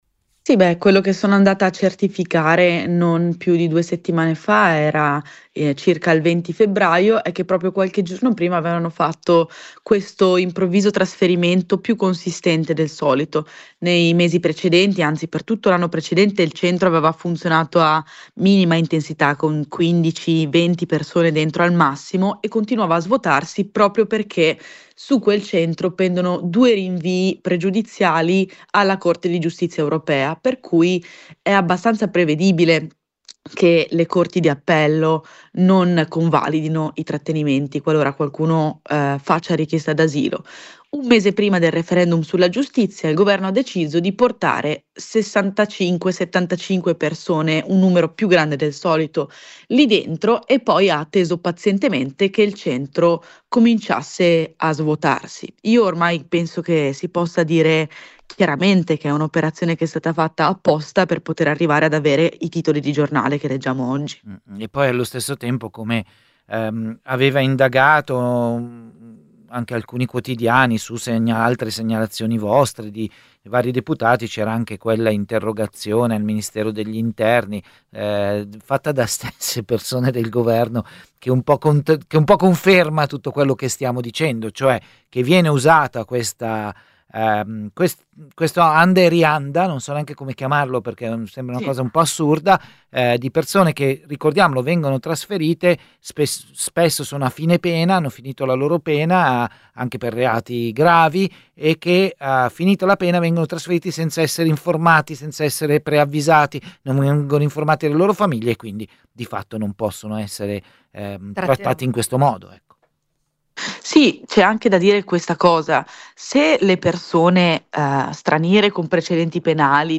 Come ci spiega la deputata del PD Rachele Scarpa, tutto inizia col trasferimento di 65 cittadini stranieri a fine pena in Albania e finisce poi con articoli di giornale con dettagli su queste persone trasferite, le loro fedine penali e altri dati che la deputata non può ottenere ma che qualcuno al ministero ha passato ai giornalisti. Intervista